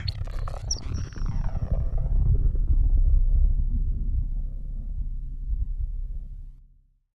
Sci-Fi Ambiences
AFX_IONSTORM_2_DFMG.WAV